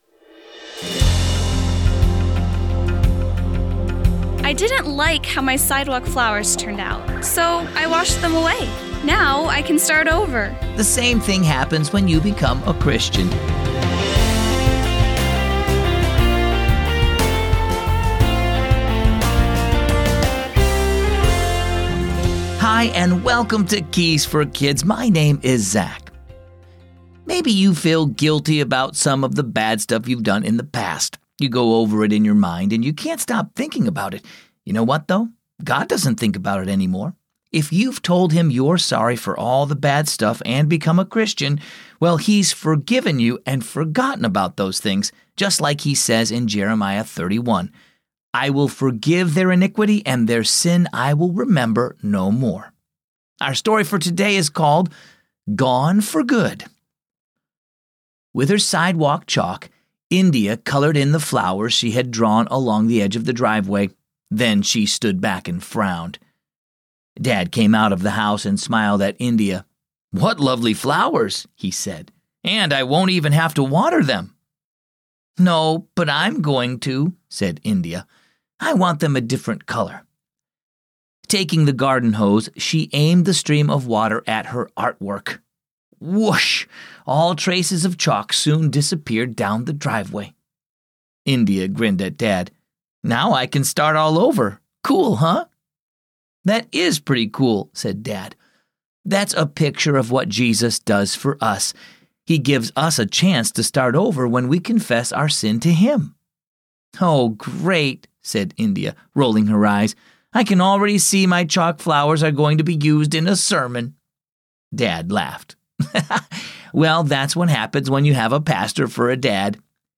creative voices bringing characters to life